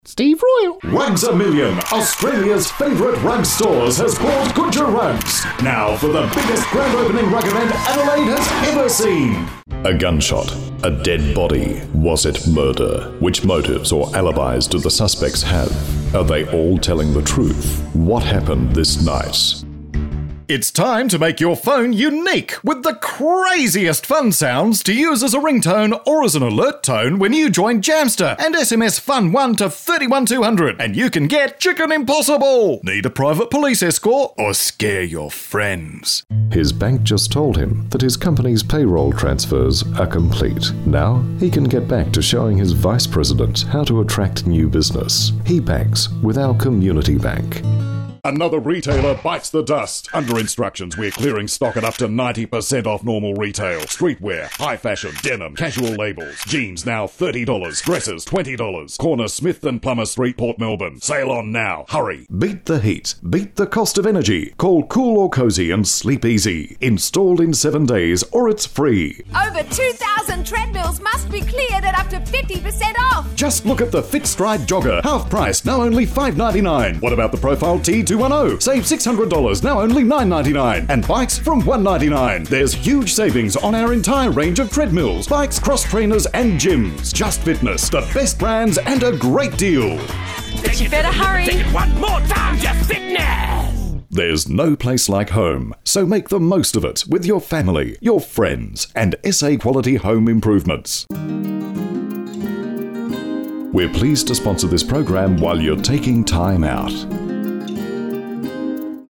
Strong, crisp,versatile, convincing, authoritative, cheeky voice artist
englisch (australisch)
Sprechprobe: Werbung (Muttersprache):